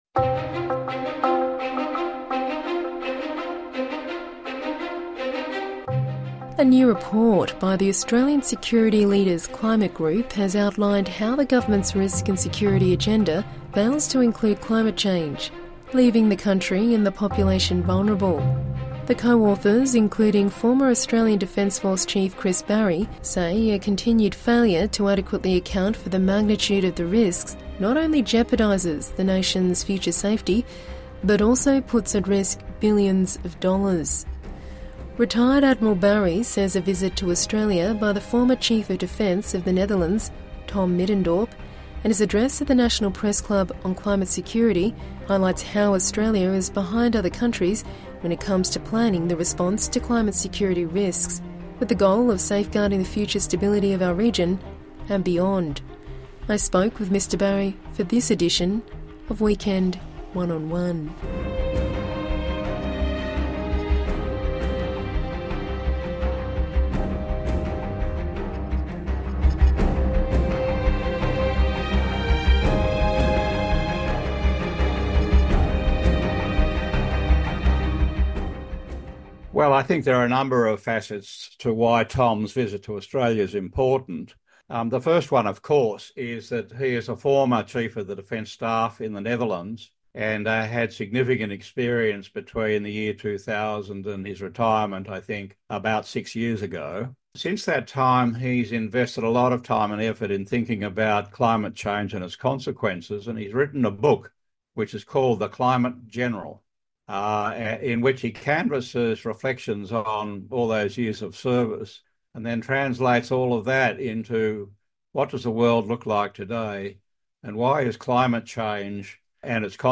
INTERVIEW: Former defence chief Chris Barrie talks to SBS about Australia's climate security